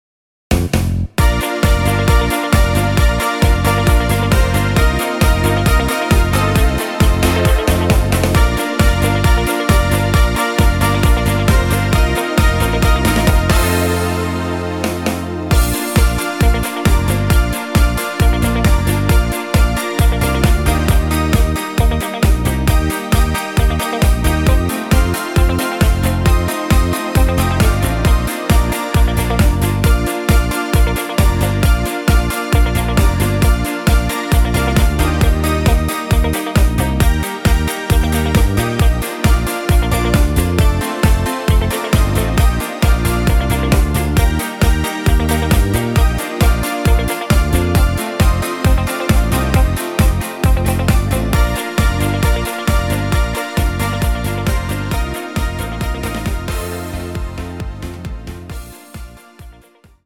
ein netter Titel zum Tanzen